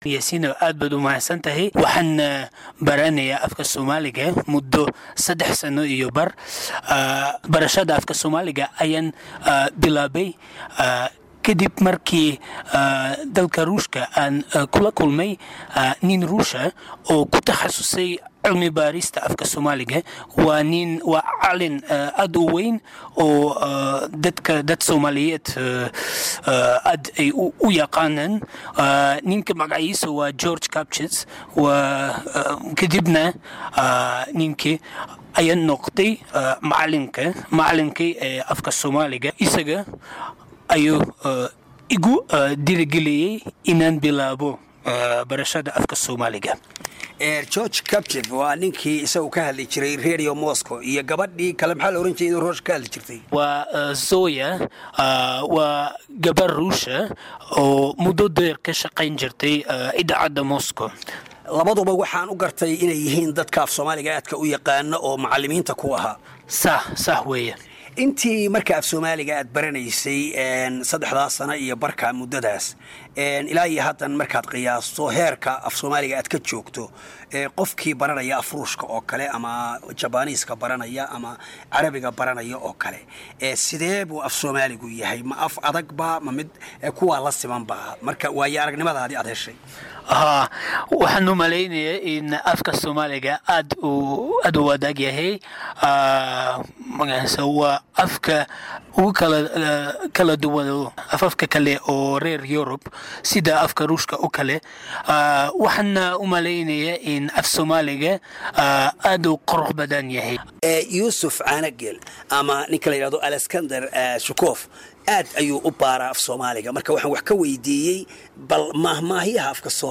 Embed share Wareysi